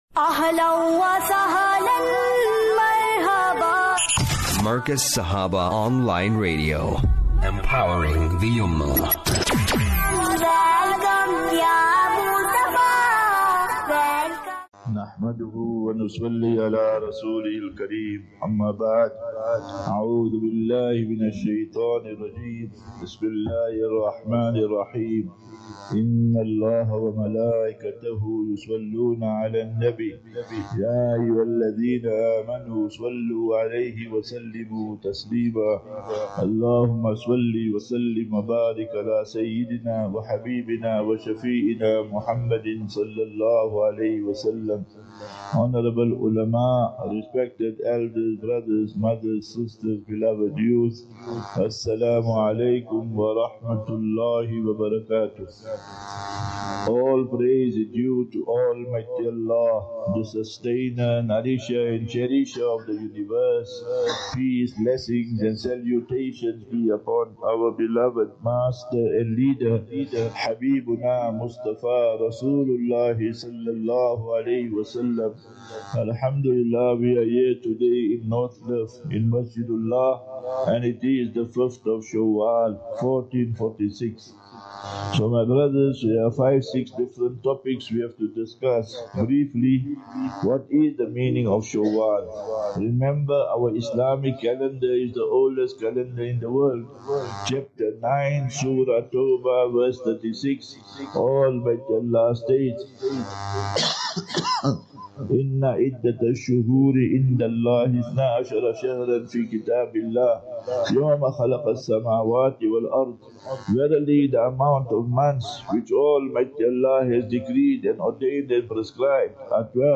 4 Apr 04 April 25 - Jumu'ah Lecture at MASJIDULLAH - NORTHCLIFF (JHB)